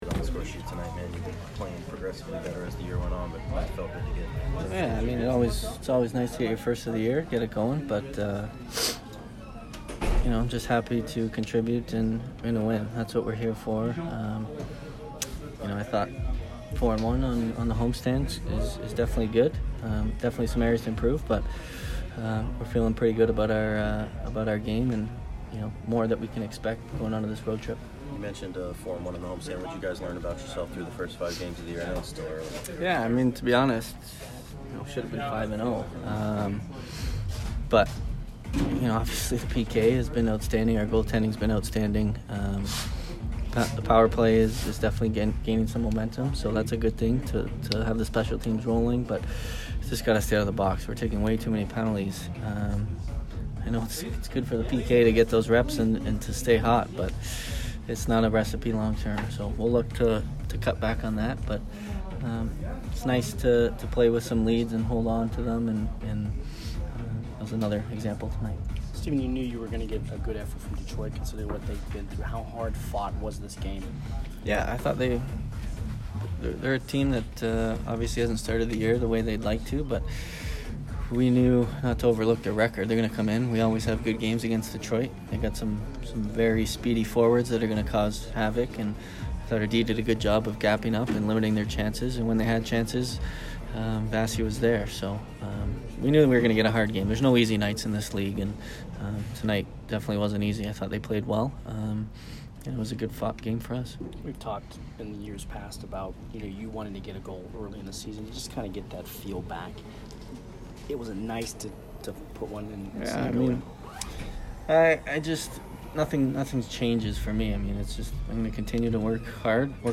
Steven Stamkos post-game 10/18